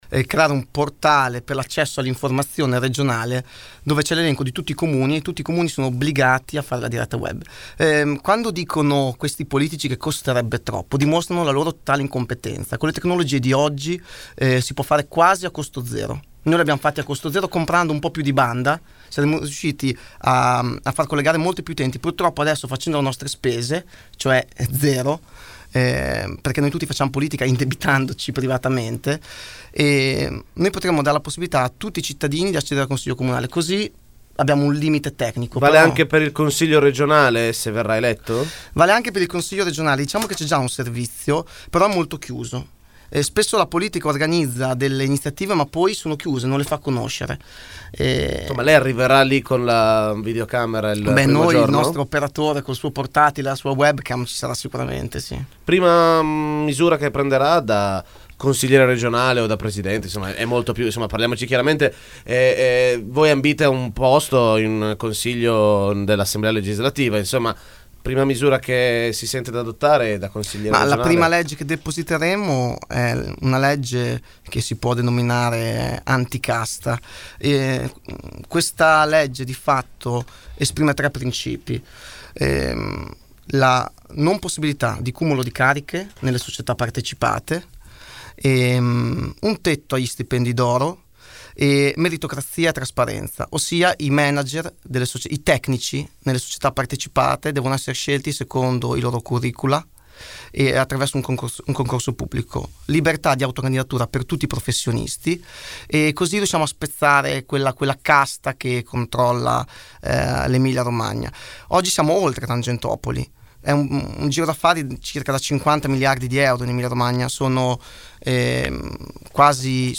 Ecco una sintesi dell’intervista andata in onda questa mattina.